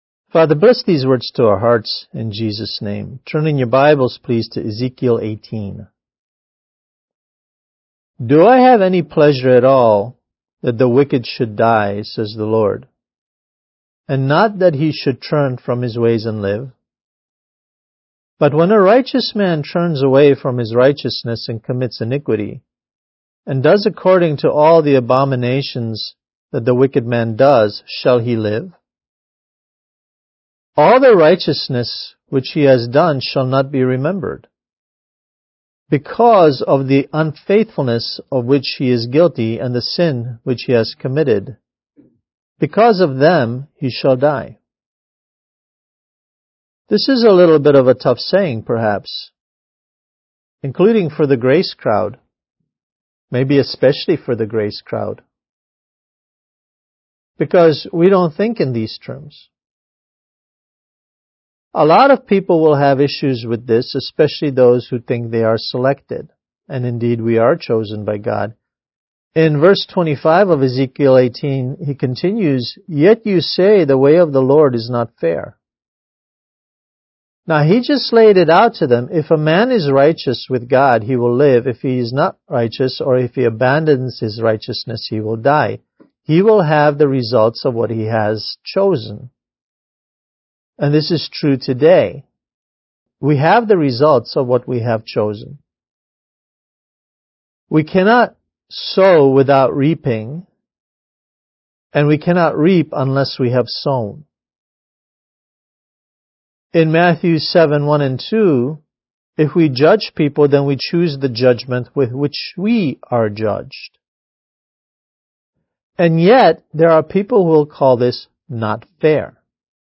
Kids Message: Is God Fair?